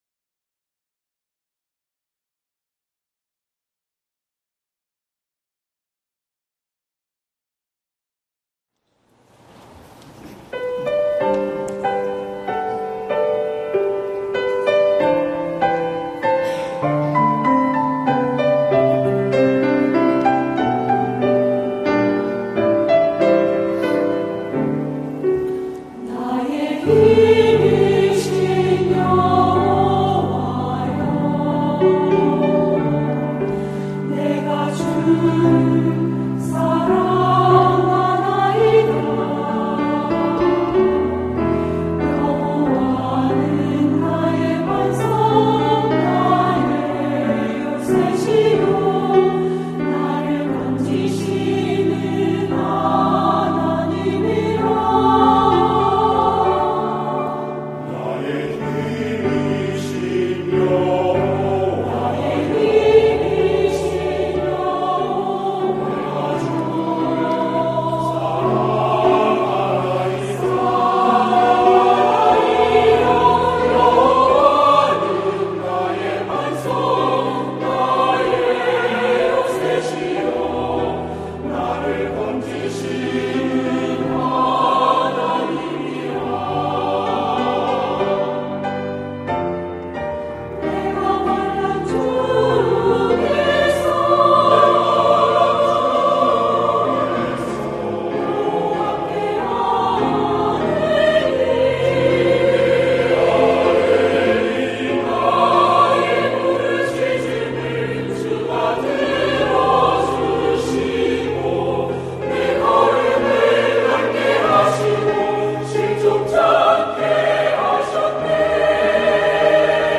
나의 힘이신 여호와여 > 찬양영상